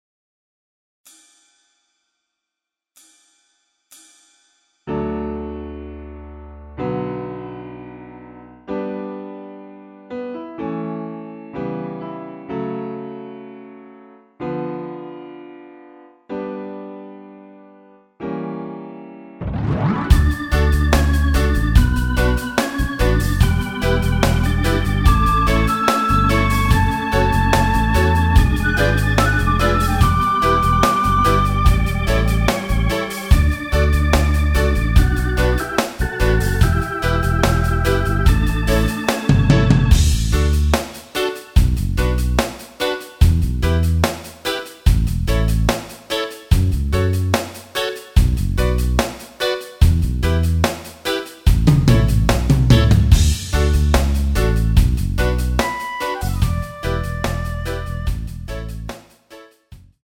전주 없이 시작 하는곡이라 카운트 넣었으며 엔딩이 페이드 아웃이라 엔딩도 만들어 놓았습니다.(미리듣기 참조)
◈ 곡명 옆 (-1)은 반음 내림, (+1)은 반음 올림 입니다.
앞부분30초, 뒷부분30초씩 편집해서 올려 드리고 있습니다.
중간에 음이 끈어지고 다시 나오는 이유는